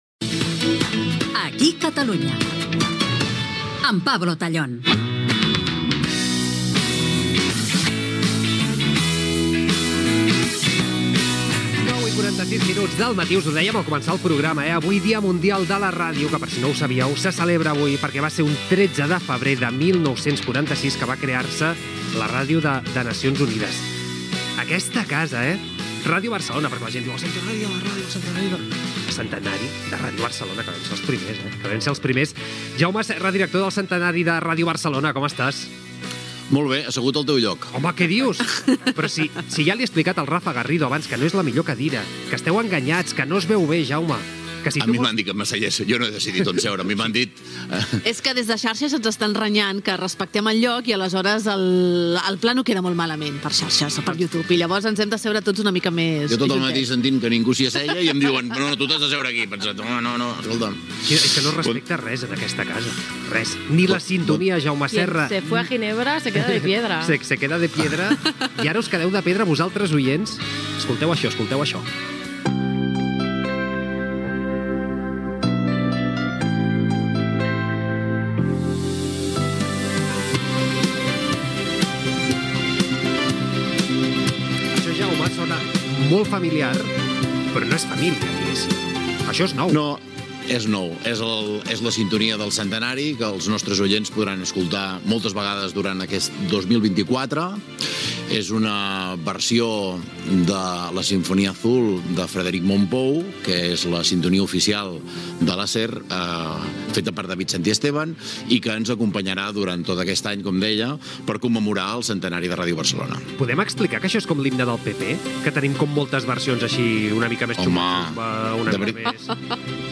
Indicatiu del programa i entrevista
Gènere radiofònic Info-entreteniment